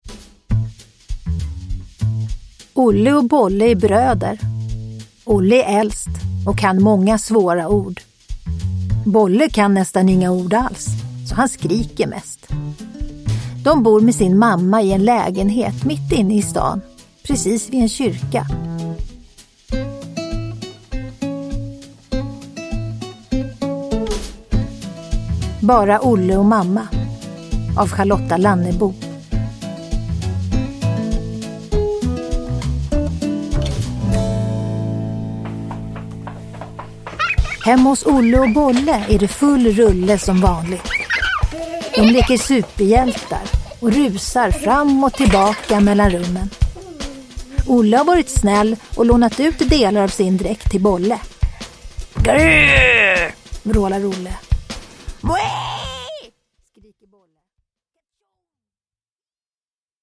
Bara Olle och mamma – Ljudbok – Laddas ner
Uppläsare: Tova Magnusson